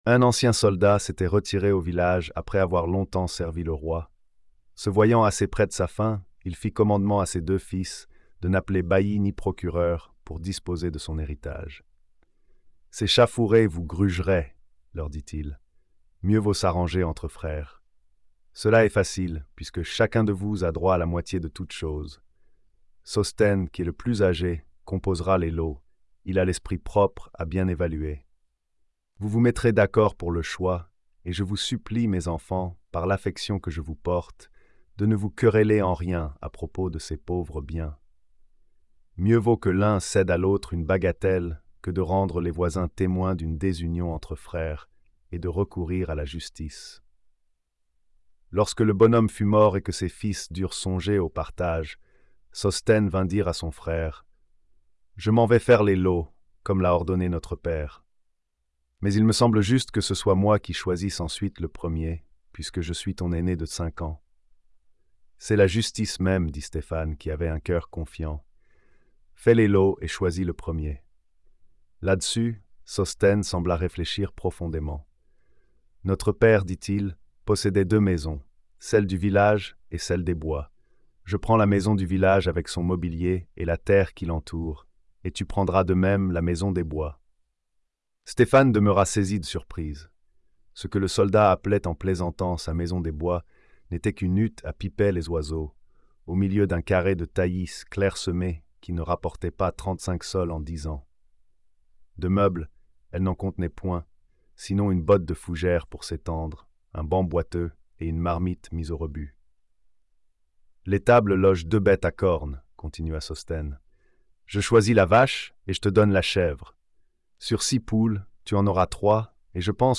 Lecture audio générée par IA